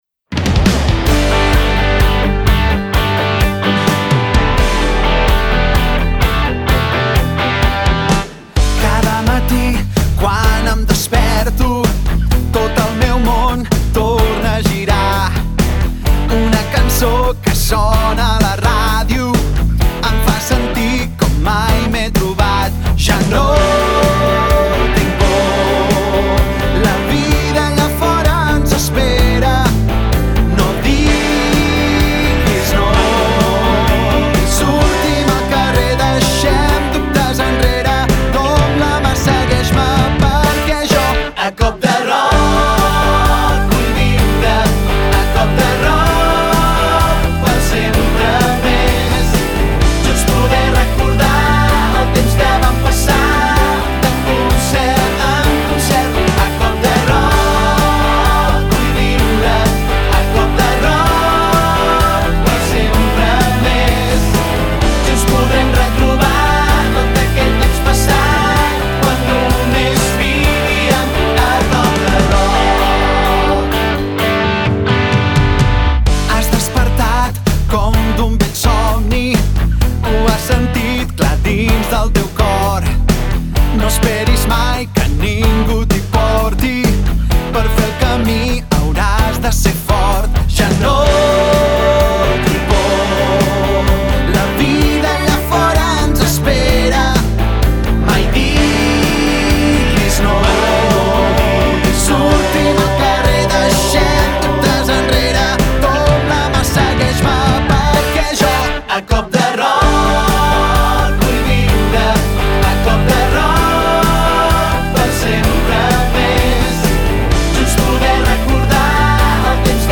cançó